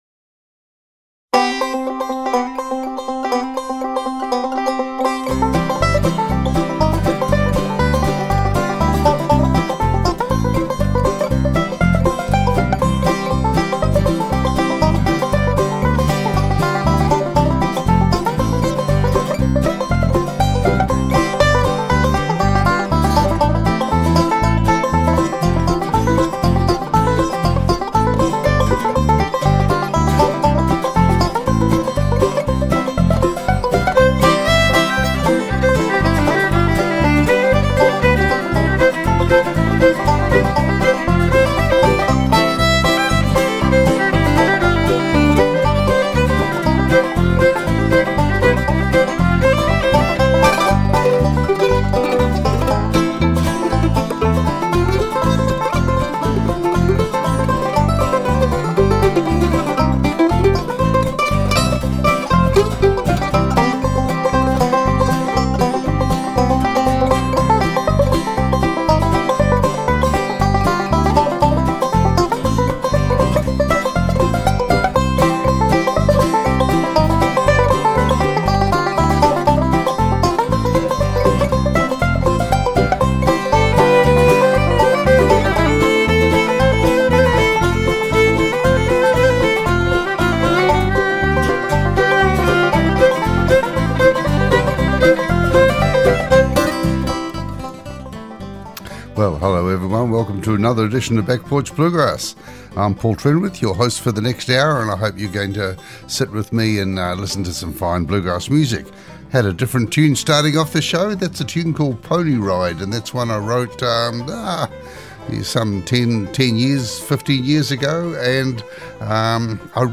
Back Porch Bluegrass Show